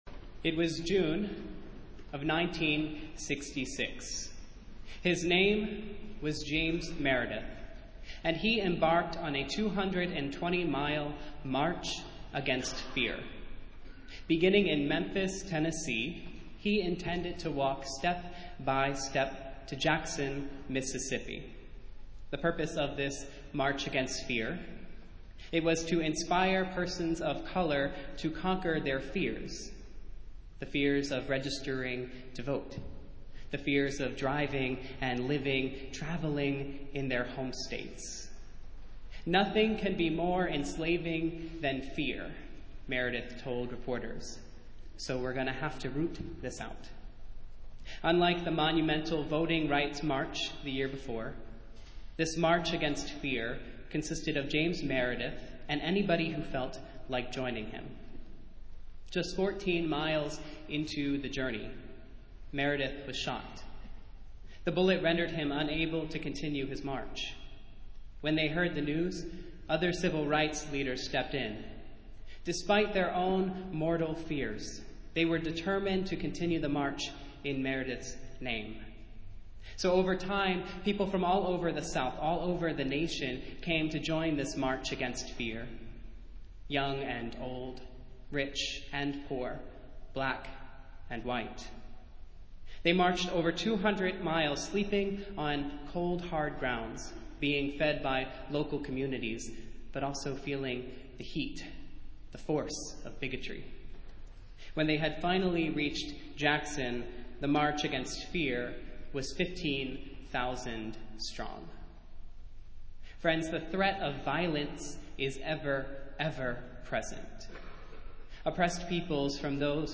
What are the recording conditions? Festival Worship - Second Sunday in Lent